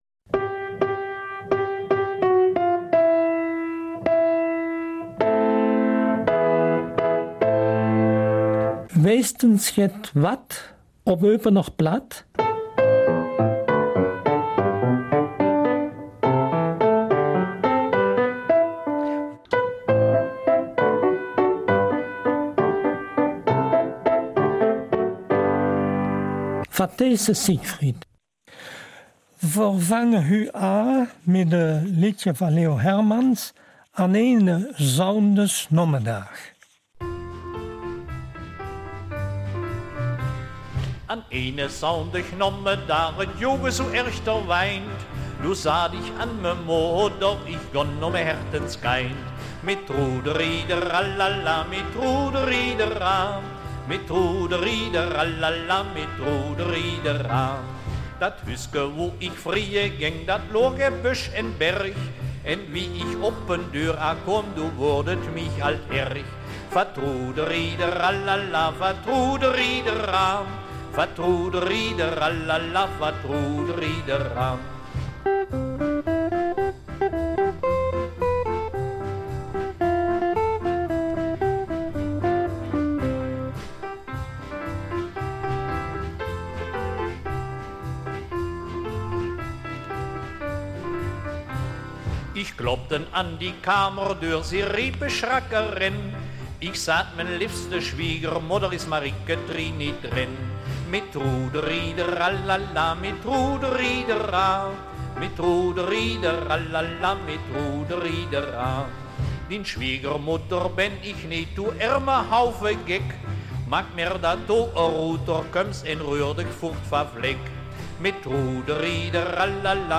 Eupener Mundart